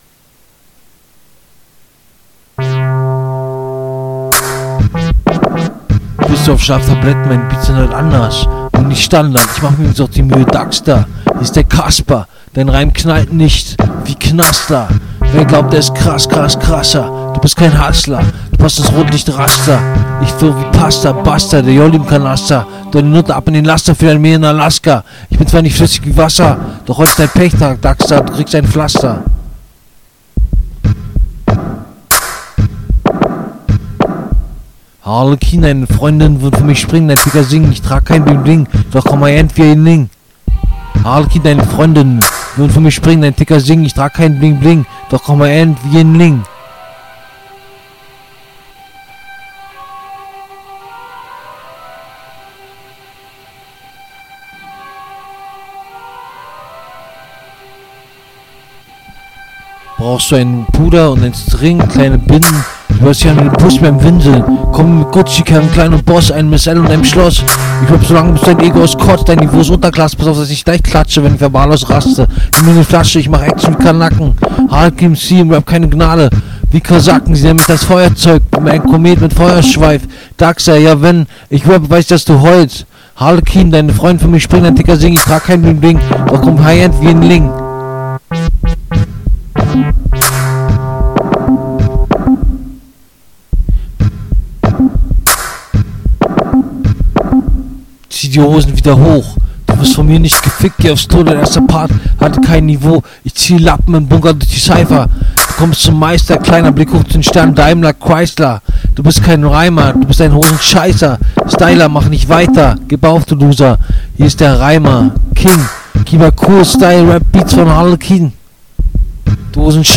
Falscher Beat - kein Punkt.